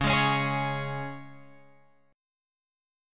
C#5 chord {9 x 6 6 9 9} chord